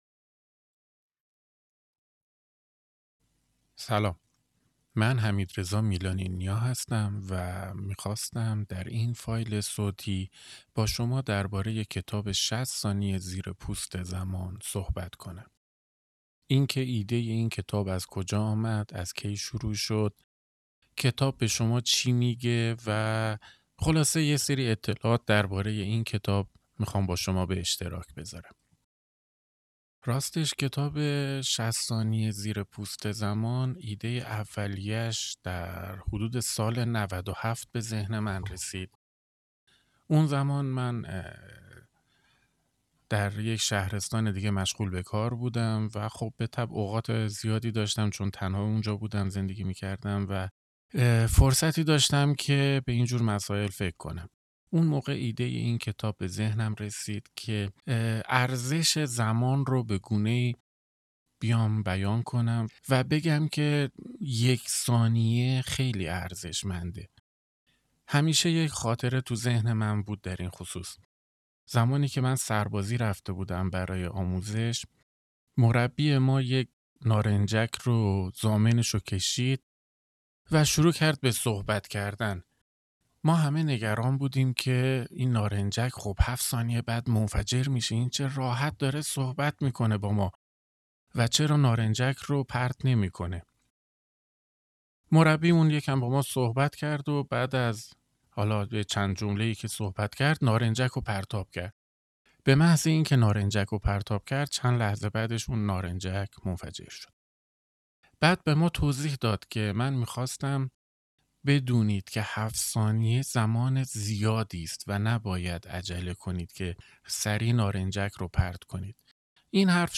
کتاب صوتی ۶۰ ثانیه زیر پوست زمان | اپیزود صفر: مقدمه زمان فقط یک عدد نیست، یک معناست.